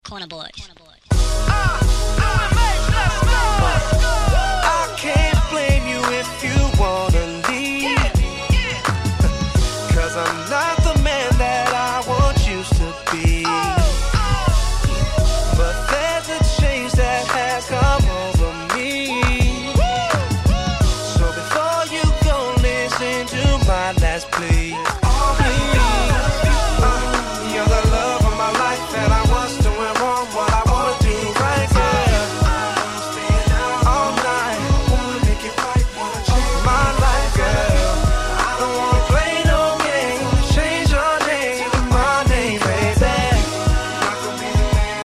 Soulfulなネタ使いの格好良い1曲。
個人的に渋くて大好きな1曲。